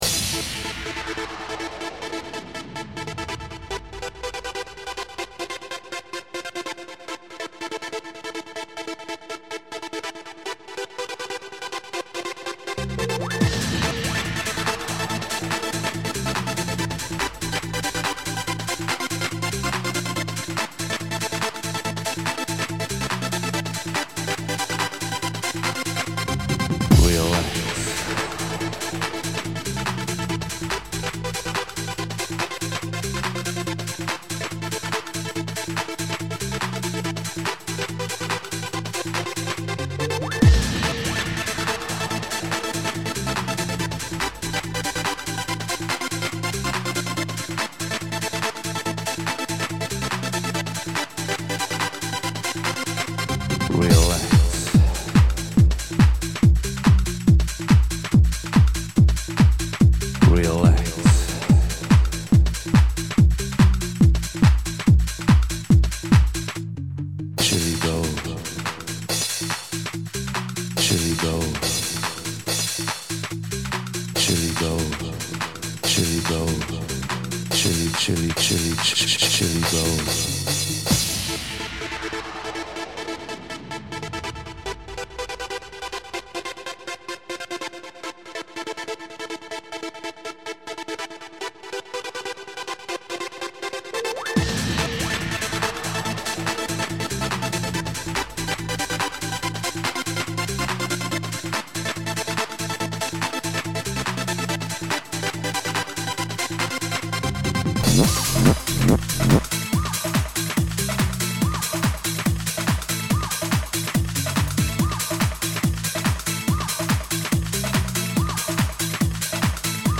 • Jakość: 44kHz, Stereo